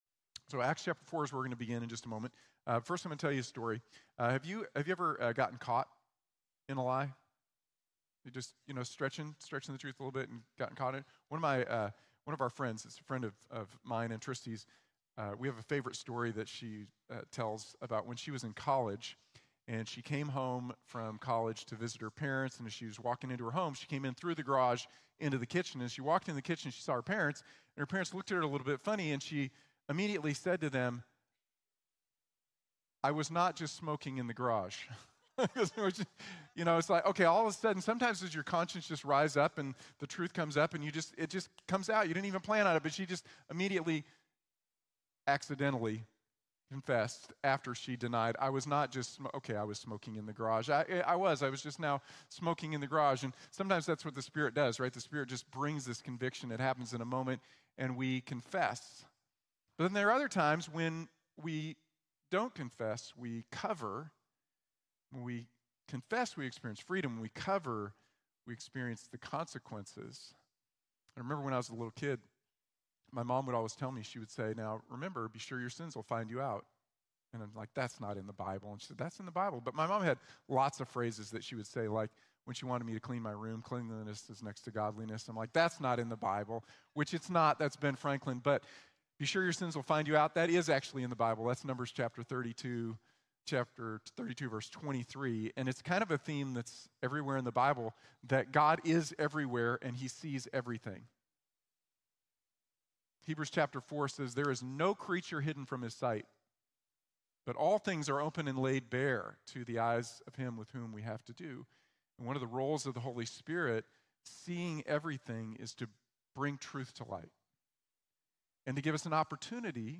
Truth or Consequences | Sermon | Grace Bible Church